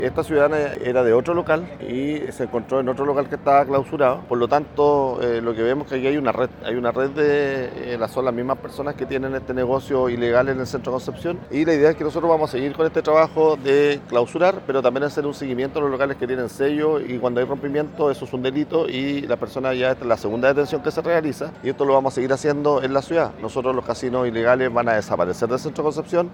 El alcalde de Concepción, Héctor Muñoz, dijo que se trata de una red que opera en la ciudad y que continuarán clausurando este tipo de negocios.